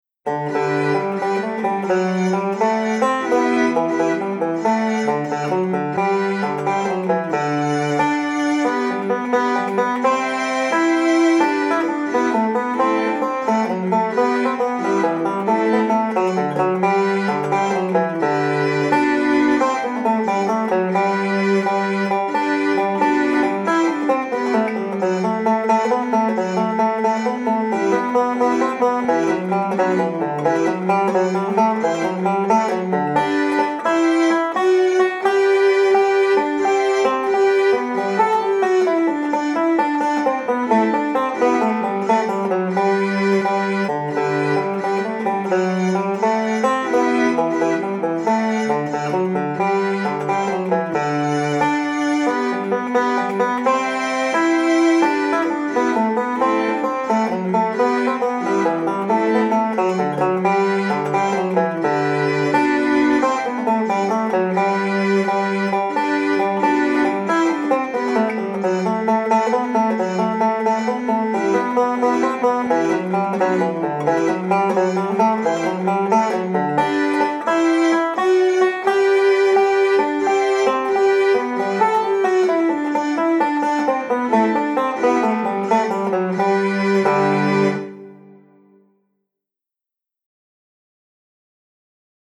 5-STRING BANJO
• Welch and Irish, Standard notation and Tab